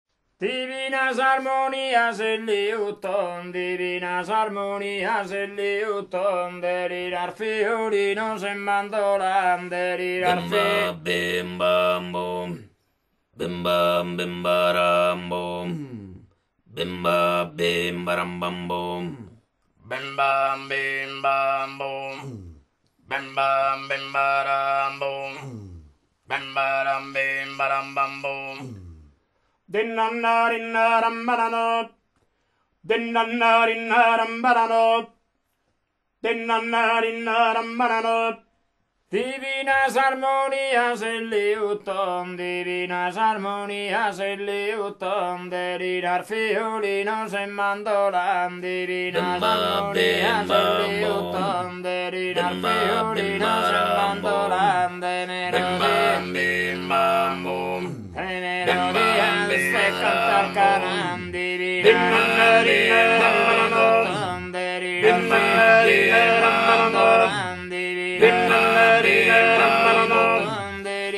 The "a tenore" songs
Ci troviamo di fronte a un modo di cantare molto particolare soprattutto come emissione vocale, quindi molto interessante dal punto di vista timbrico. Delle quattro voci due sono gutturali : su bassu (basso) e sa contra (contralto).
Contra e bassu procedono in parallelo nella scansione delle sillabe non-sens (bim bam boo) senza che l'una o l'altra voce cambi nota prima del cenno della voce solista.